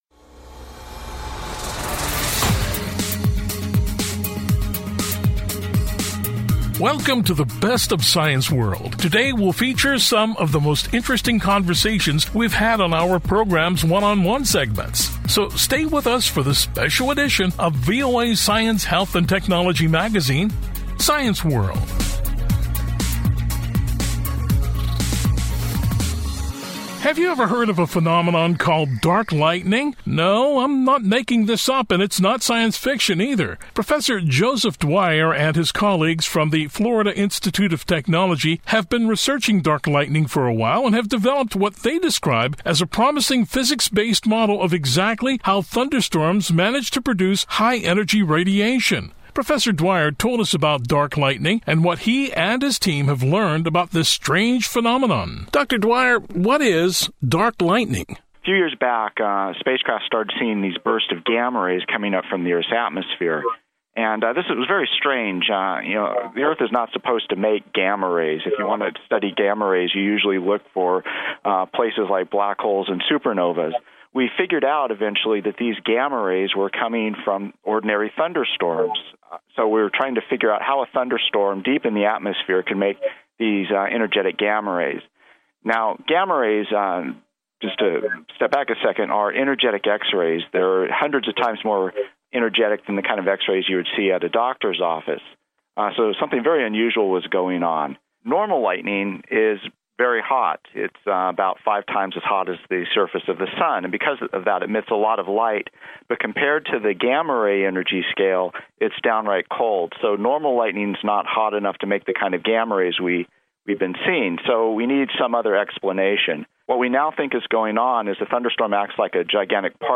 On this program we feature four of our One on One interview segments from past Science World programs.